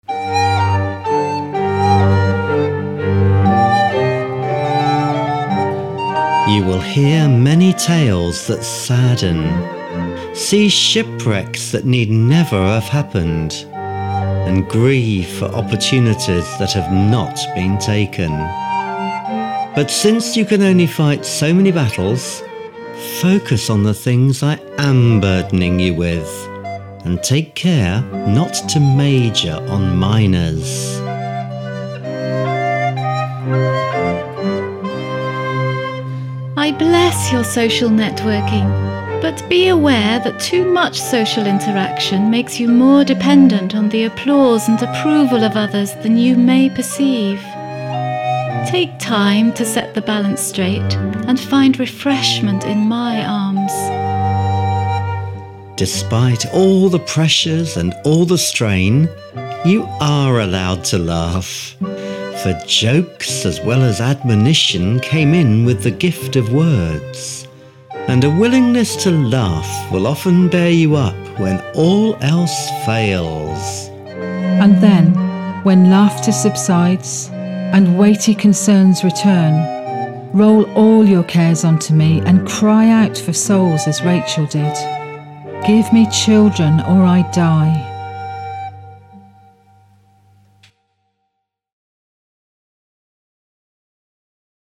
Vivaldi Minuet - Trio sonata recorder, flute, cello and keyboard